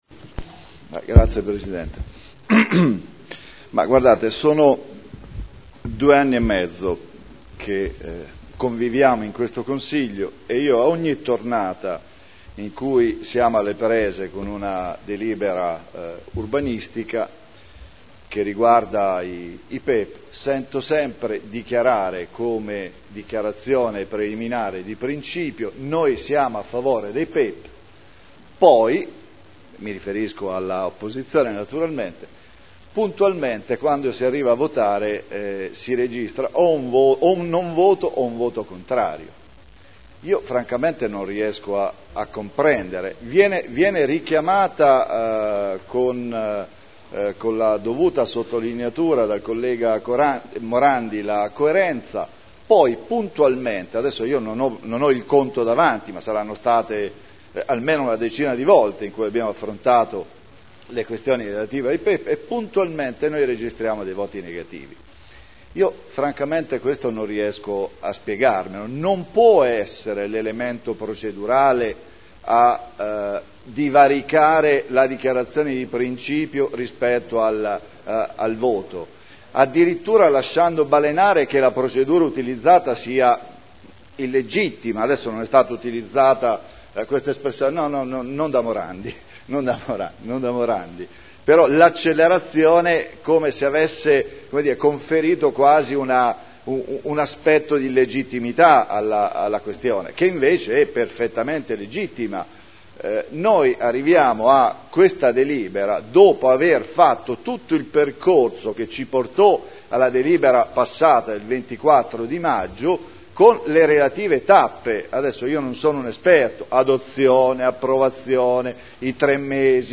Seduta del 14 novembre Zona elementare n. 50 Area 13 di proprietà comunale - Parere favorevole all'attuazione delle previsioni urbanistiche dell'area con permesso di costruire convenzionato in deroga al Piano particolareggiato (Art. 31.23 RUE) Dichiarazioni di voto